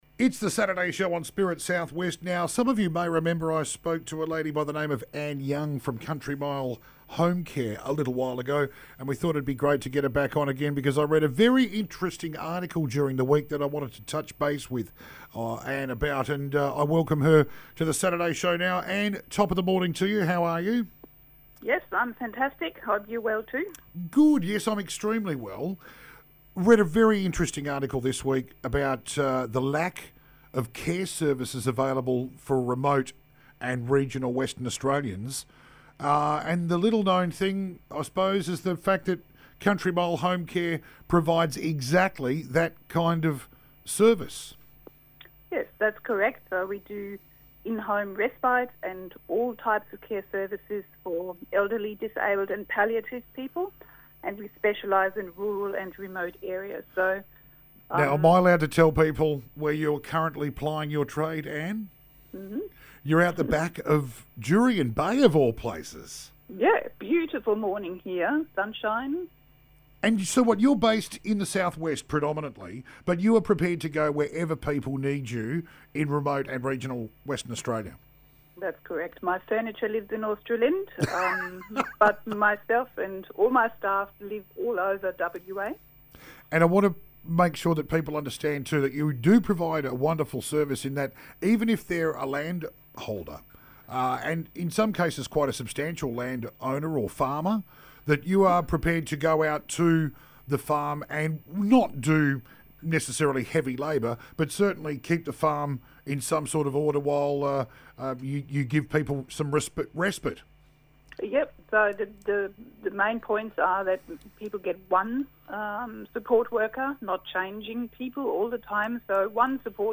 Interview #1: Country Mile Home Care